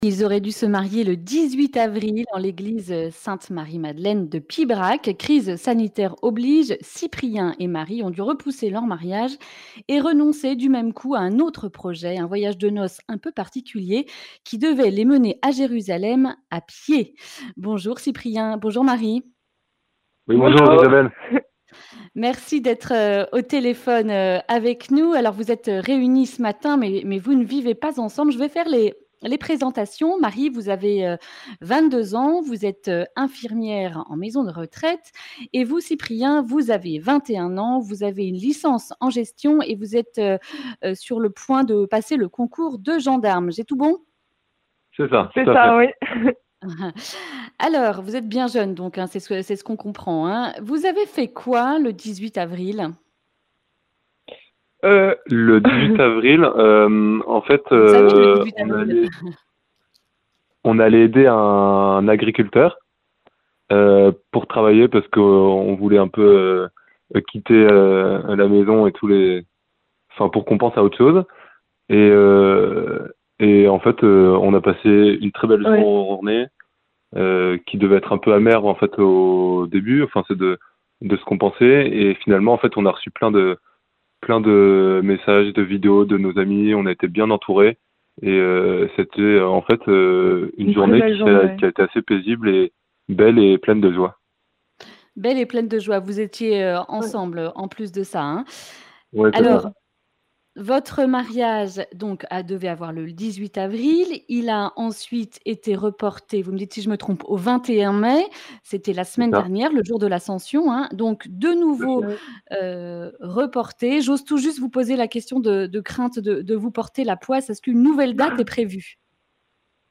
Accueil \ Emissions \ Information \ Régionale \ Le grand entretien \ Leur mariage a été reporté deux fois !